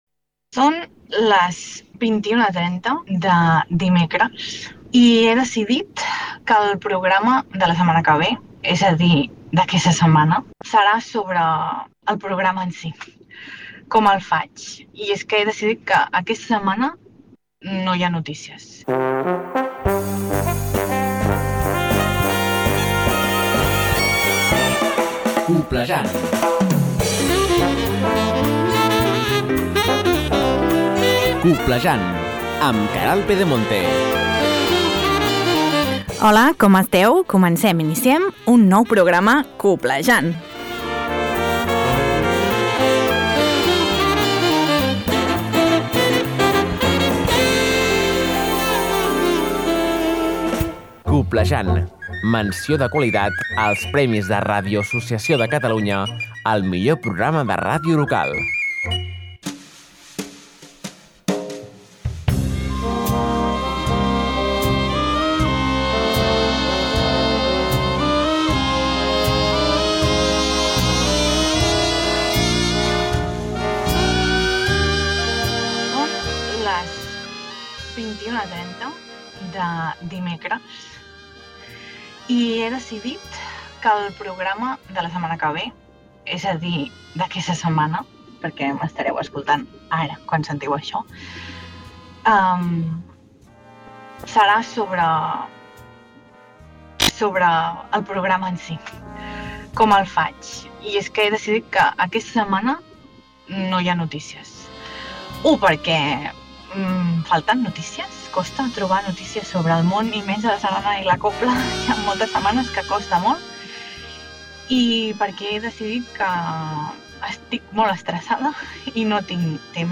T’informa de tot allò que és notícia al món immens de la sardana i la cobla.